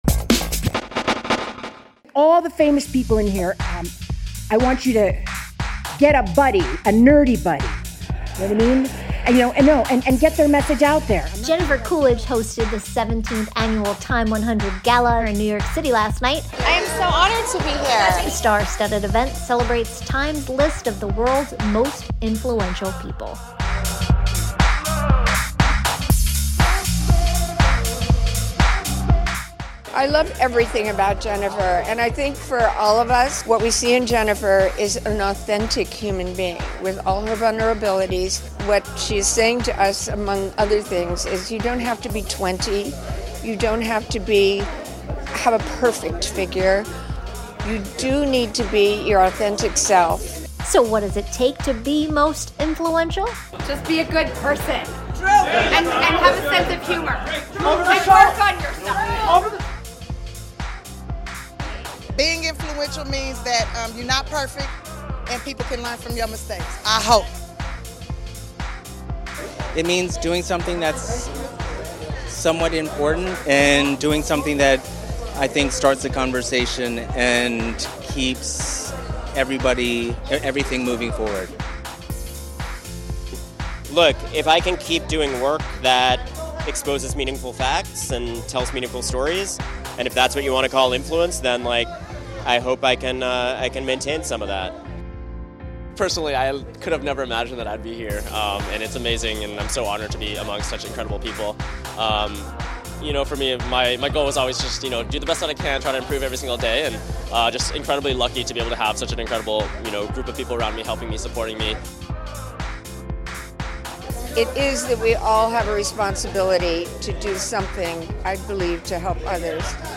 TIME held its 17th annual TIME100 Gala, celebrating TIME's list of the world's most influential people, at Frederick P. Rose Hall, home of Jazz at Lincoln Center in New York City last night.
We chatted with some of the stars and honorees to ask what it means to be influential.